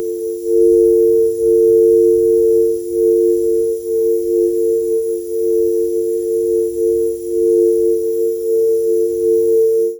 Subtle ambient music with faint synthesizer sound effects blends gently into the natural jungle sounds. 0:47 Created Jan 16, 2025 12:16 PM Pure silence, the absence of any sound. In 3D spatial. 0:10 Created Apr 9, 2025 3:20 PM
pure-silence-the-absence--3ijldznf.wav